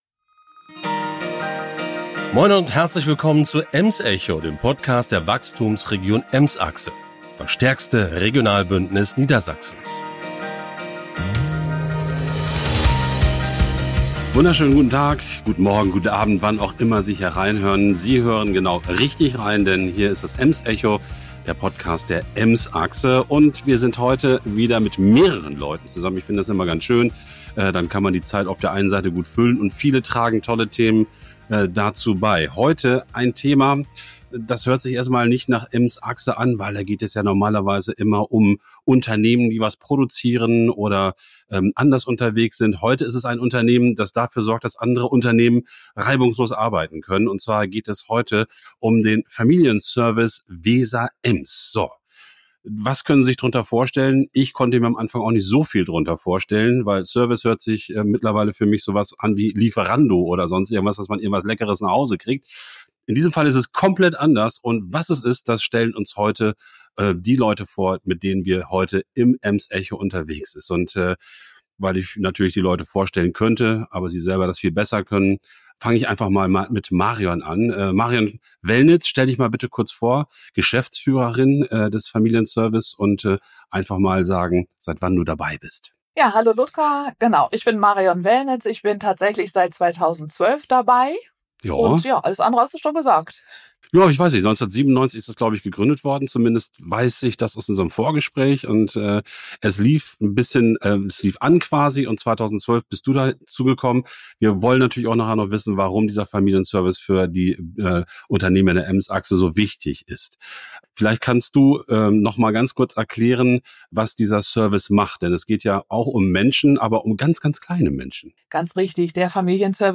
im Studio. Im Gespräch geben sie Einblicke in die Herausforderungen, mit denen Familien und berufstätige Eltern heute konfrontiert sind, und die Lösungen, die der Familienservice allen Unternehmen und Mitgliedern des Netzwerks bietet. Sie erläutern die verschiedenen Aspekte der Kinderbetreuung, einschließlich der Eingewöhnung, der Rolle der Tagespflege und der finanziellen Unterstützung für Familien.